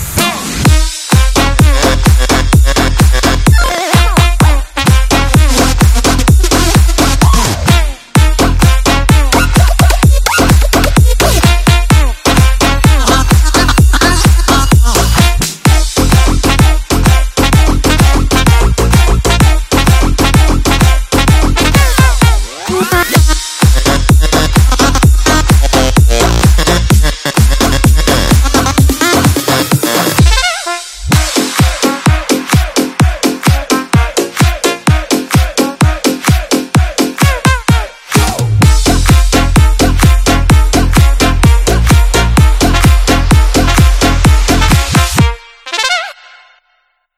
• Качество: 320, Stereo
заводные
Electronic
EDM
без слов
electro house
ремикс
Melbourne Bounce
Оригинальная обработка народной русской песни